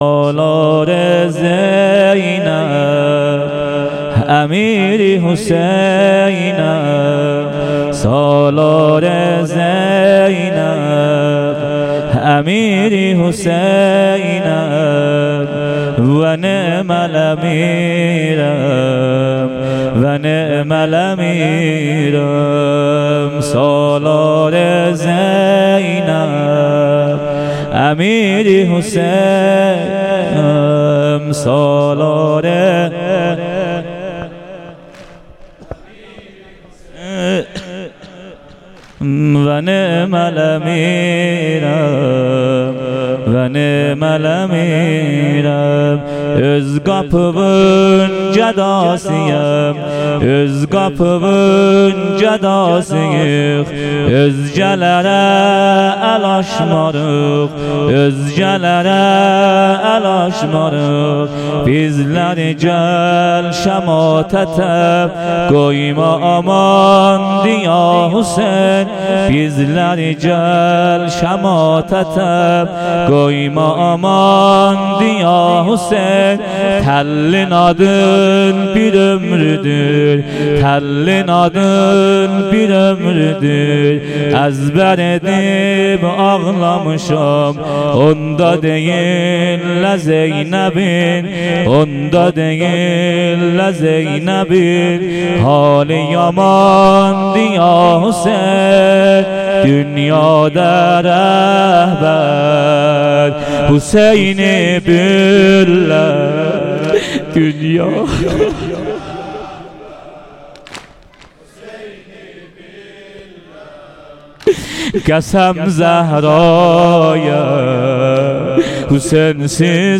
شب چهارم محرم 98 - بخش دوم سینه زنی (واحد)